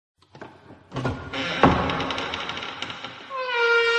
Free Horror sound effect: Creaking Door.
Creaking Door
Creaking Door is a free horror sound effect available for download in MP3 format.
044_creaking_door.mp3